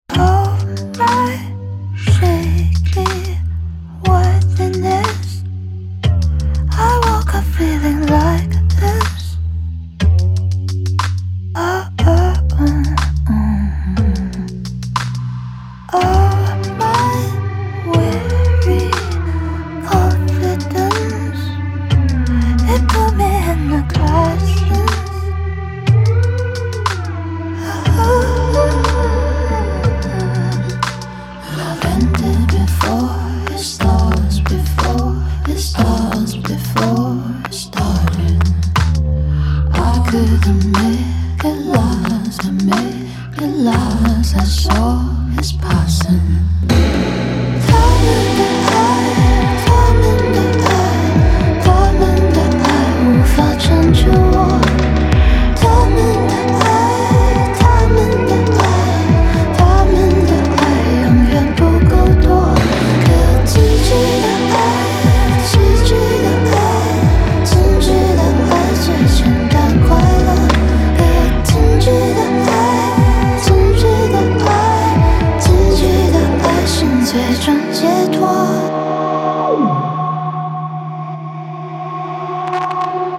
Down Tempo